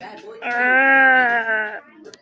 PLAY RAAAAH
Play, download and share AAAh original sound button!!!!